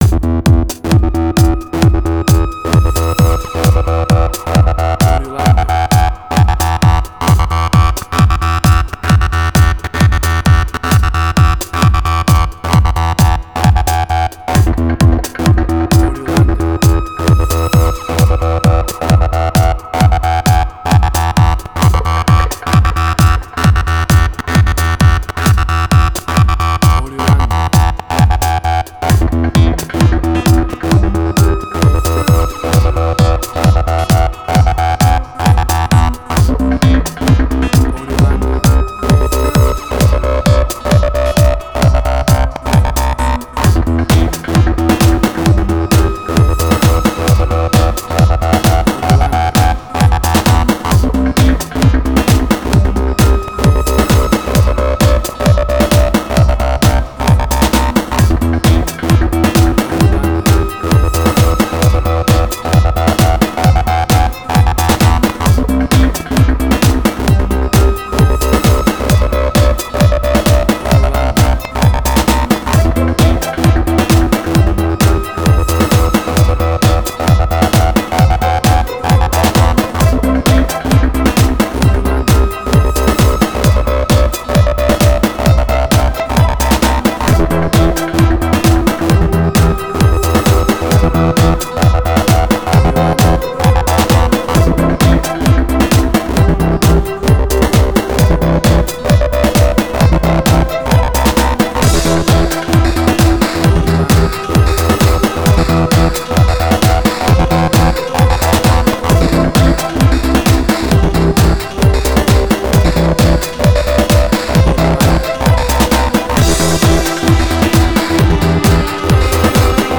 House.
Tempo (BPM): 130